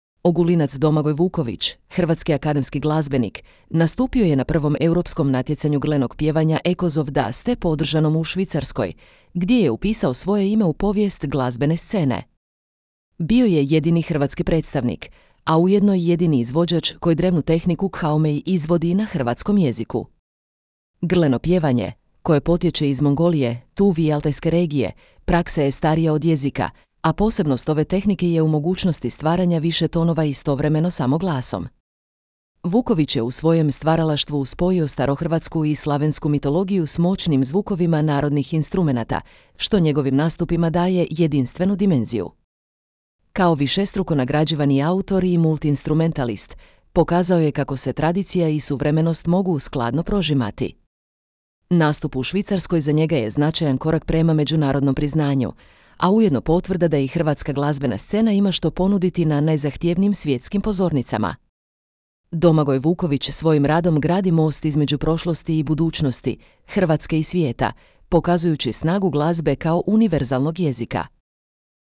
grlenog pjevanja
Grleno pjevanje, koje potječe iz Mongolije, Tuve i Altajske regije, praksa je starija od jezika, a posebnost ove tehnike je u mogućnosti stvaranja više tonova istovremeno samo glasom.